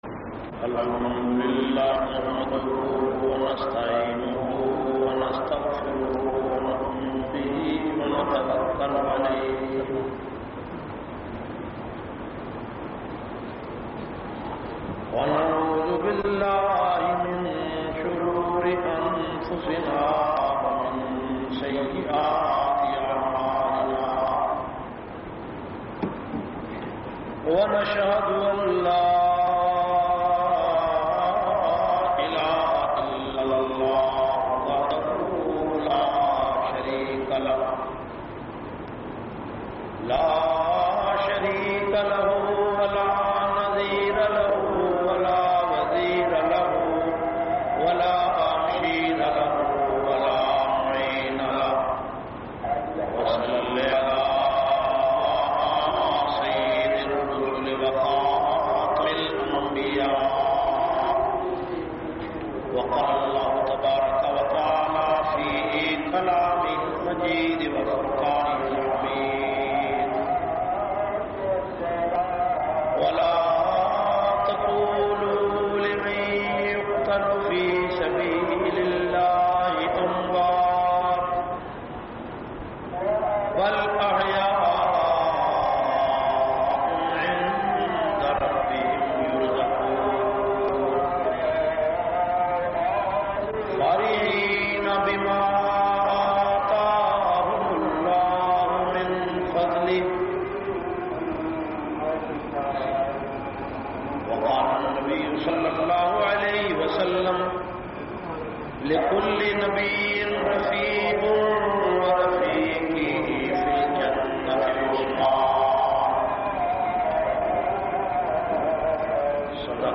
446- Shahadat Usman Ghani Jumma khutba Jamia Masjid Muhammadia Samandri Faisalabad.mp3